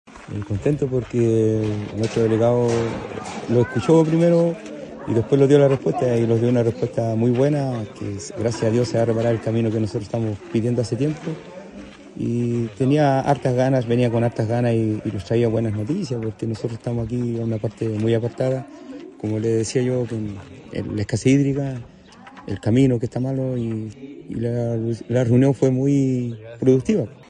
En la sede social de la localidad de Matancilla, se llevó a cabo una reunión de trabajo en que los vecinos del sector dieron a conocer sus necesidades al Delegado presidencial de Choapa, Jonatan Vega, quién acompañado de los servicios públicos como Vialidad, Dirección General de Aguas y la Municipalidad de Illapel escucharon atentamente cada una de las exposiciones de los participantes que se centraron en dos muy importantes: el arreglo del camino principal de acceso al sector y una mayor coordinación respecto a la entrega del agua potable.